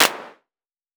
Vermona Clap 02.wav